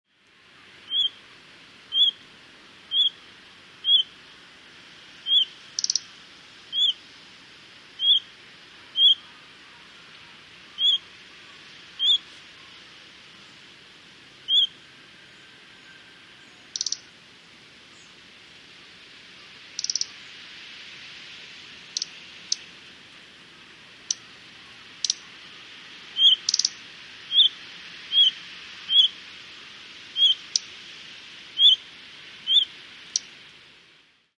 ojirobitaki_c1.mp3